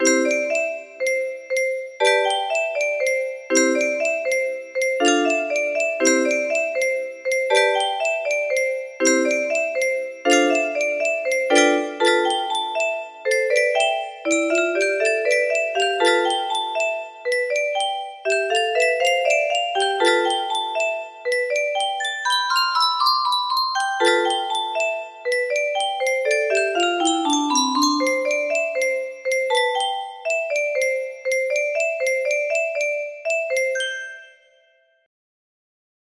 Incognito Artist-Untitled music box melody
Grand Illusions 30 (F scale)